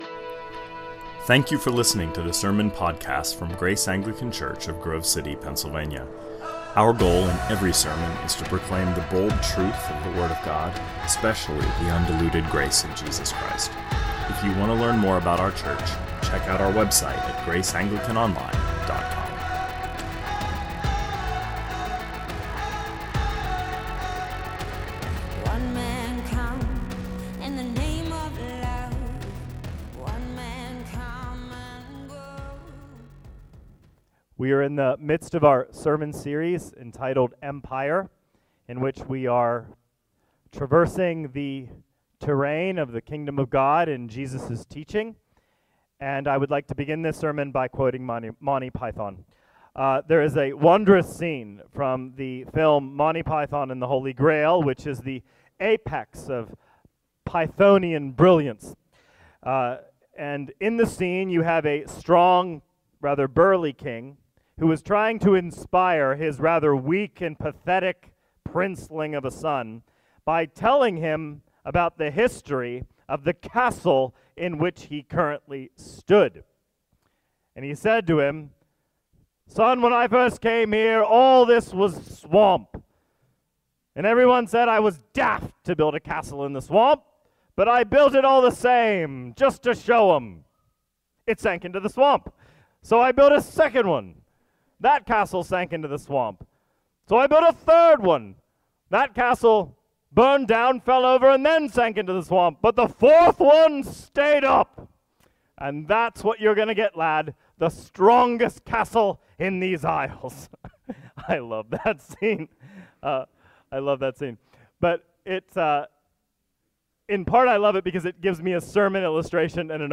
2024 Sermons Empire IX - The Unwanted King - Matthew 21 Play Episode Pause Episode Mute/Unmute Episode Rewind 10 Seconds 1x Fast Forward 30 seconds 00:00 / 35:14 Subscribe Share RSS Feed Share Link Embed